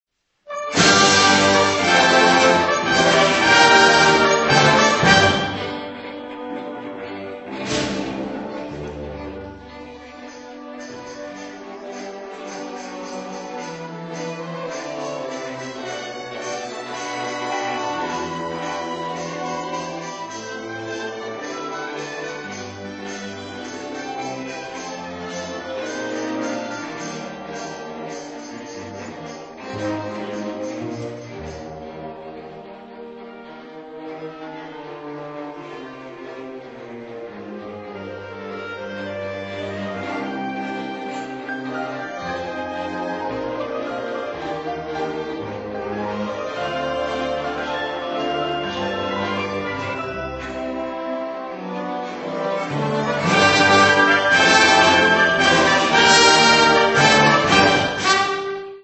Concert on Sunday 25th June 2000
Peel Hall, Salford University